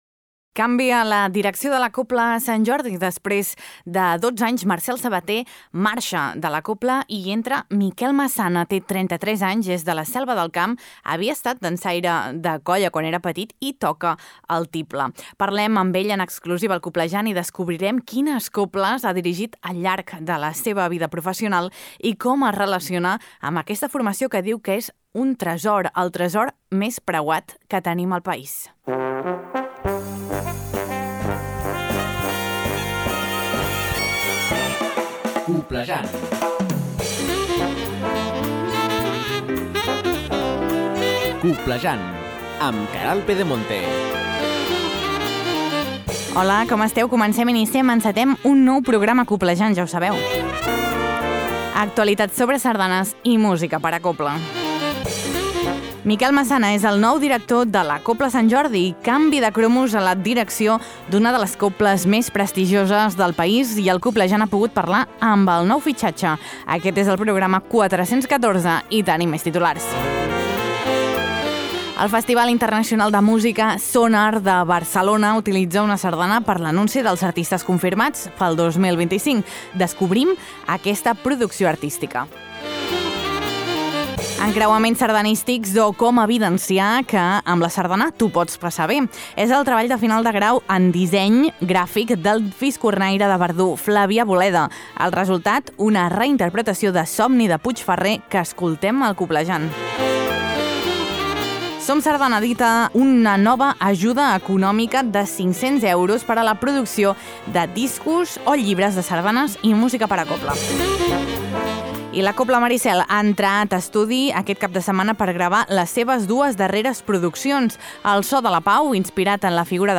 Tot això i molt més a Coblejant, un magazín de Ràdio Calella Televisió amb l’Agrupació Sardanista de Calella i en coproducció amb La Xarxa de Comunicació Local que s’emet per 75 emissores a tots els Països Catalans. T’informa de tot allò que és notícia al món immens de la sardana i la cobla.